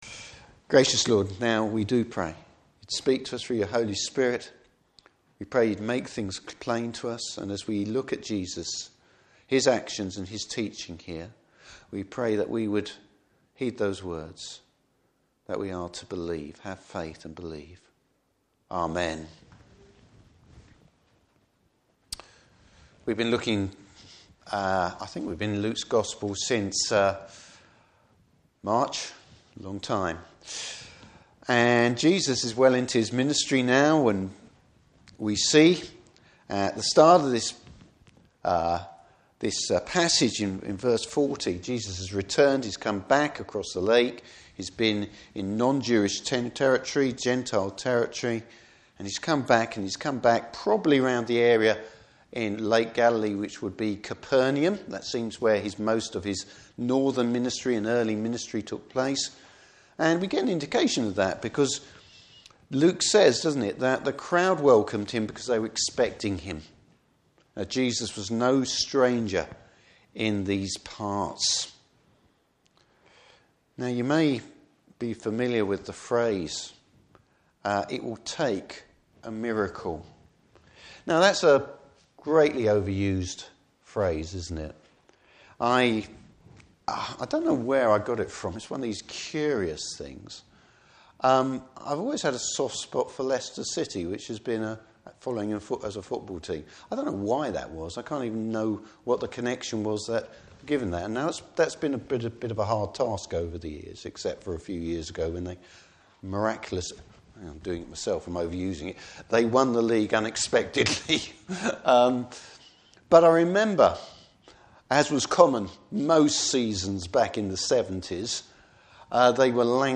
Service Type: Morning Service Bible Text: Luke 8:40-56.